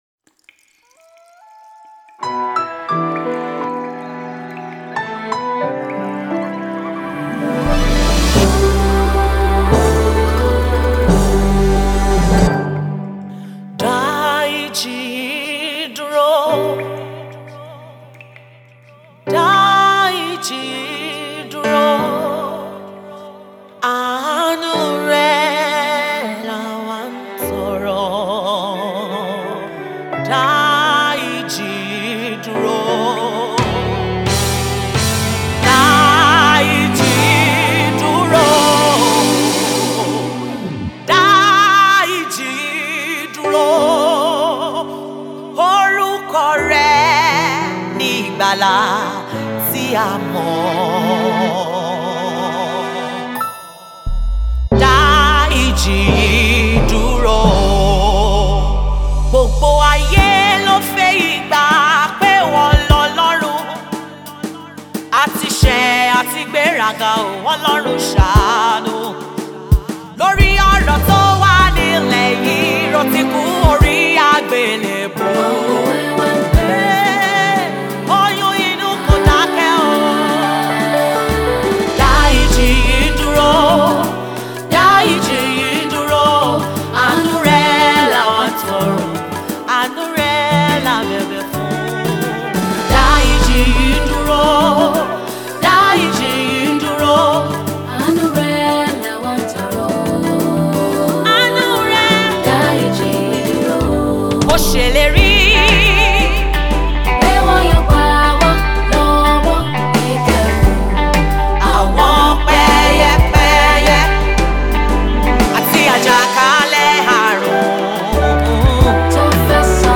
The gospel ace minister Evang.